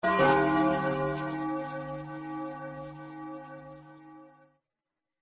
Majora's Mask Bell - Bouton d'effet sonore